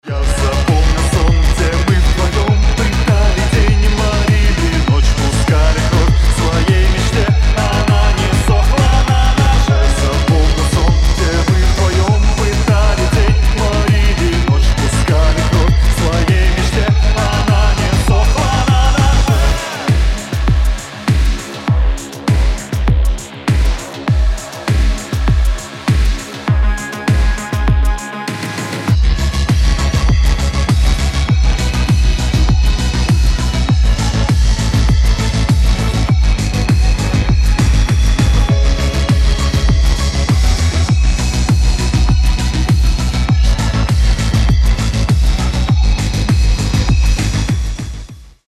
• Качество: 320, Stereo
Synth Pop
experimental
Darkwave